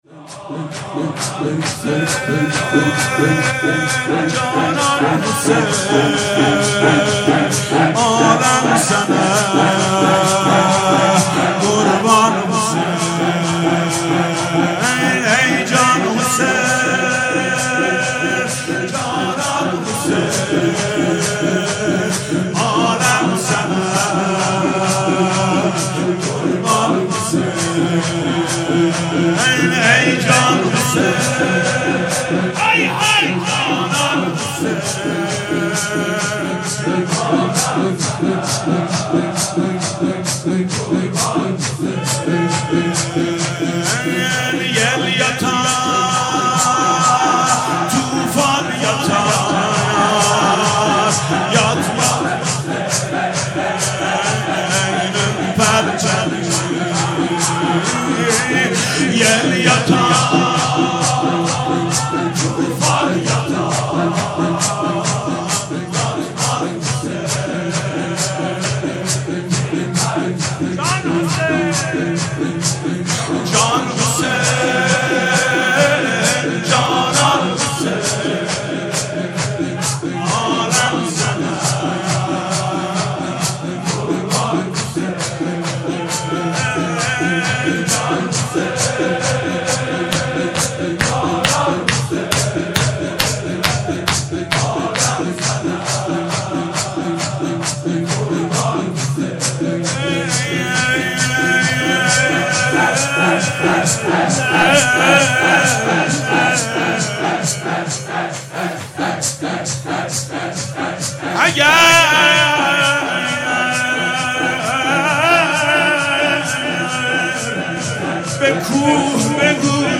مناسبت : وفات حضرت زینب سلام‌الله‌علیها
مداح : محمود کریمی قالب : شور